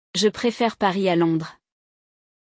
Dica de pronúncia!
O “R” francês é gutural, produzido na garganta, diferente do “R” vibrante ou retroflexo do português.